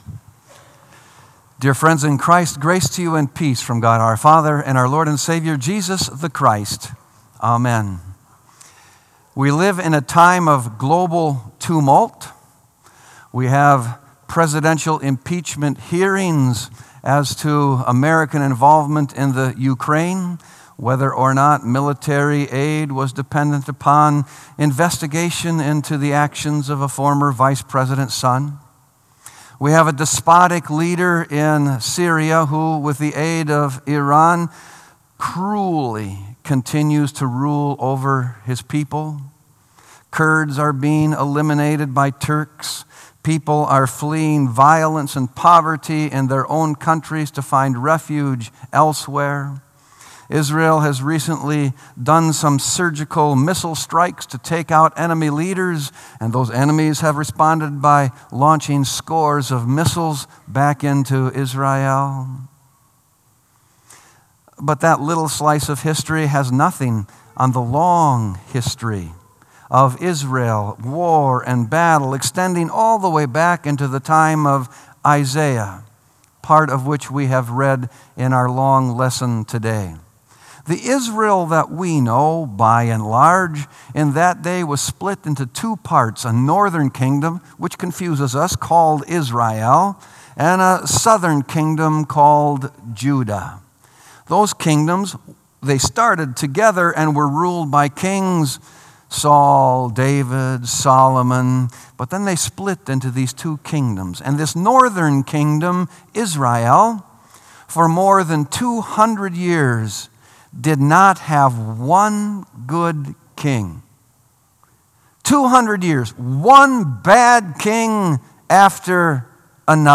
Sermon “O Lord, How Long?”